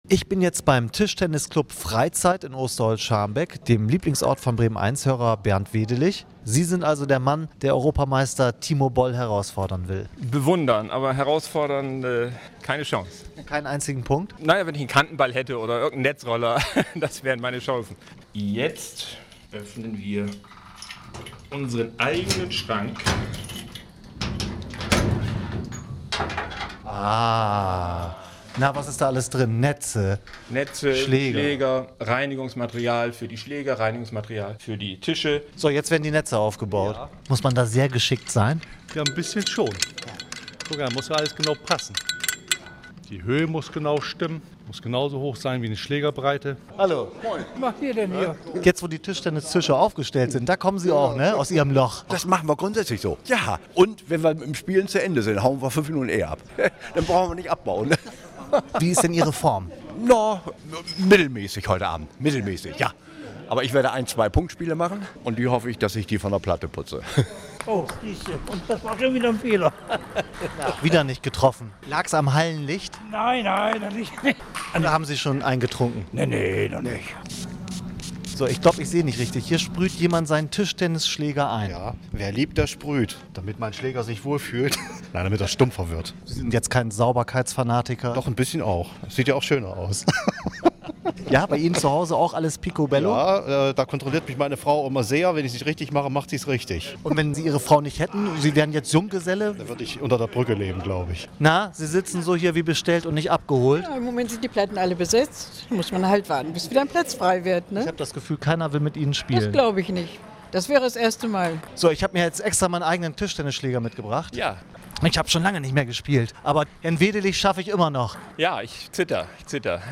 Bremen eins Reportage
Der Bericht wurde am 13. und am 15. November gesendet.
bremen_eins_reportage.mp3